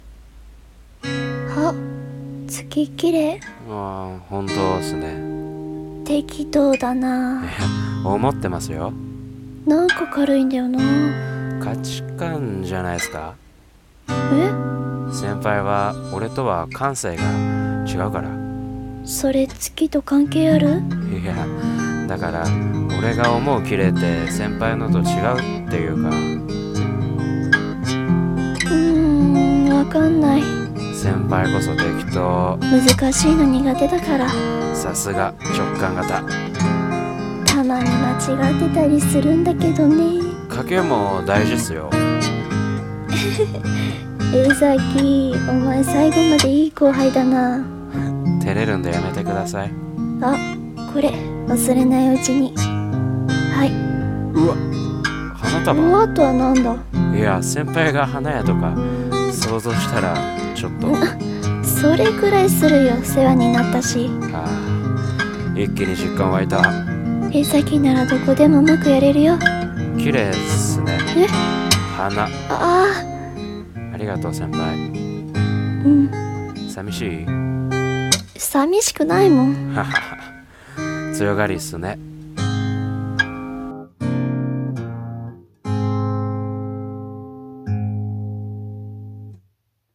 声劇】綺麗な夜